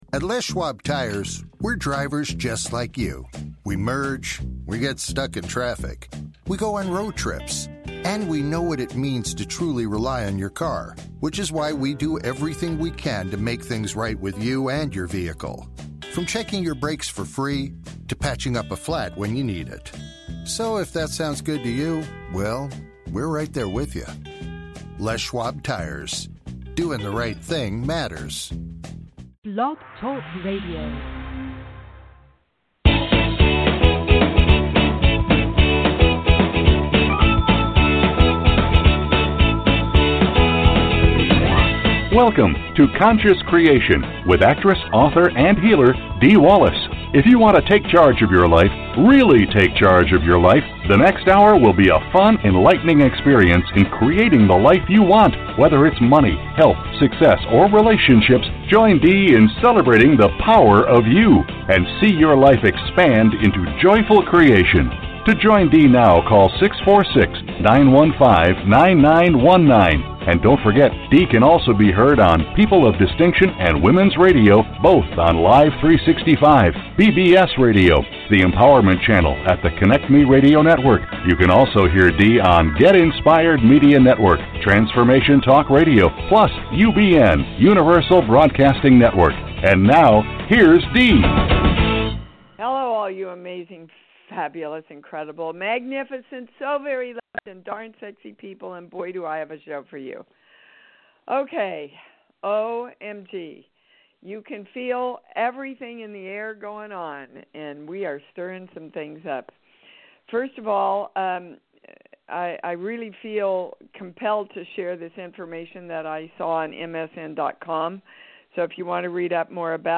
Talk Show Episode, Audio Podcast, Conscious Creation and with Dee Wallace on , show guests , about Spiritual Readings,Core Truths,Balanced Life,Energy Shifts,Spirituality,Spiritual Archaeologist,Core Issues,Spiritual Memoir,Healing Words,Consciousness, categorized as Kids & Family,Paranormal,Philosophy,Psychology,Personal Development,Spiritual,Access Consciousness,Medium & Channeling,Psychic & Intuitive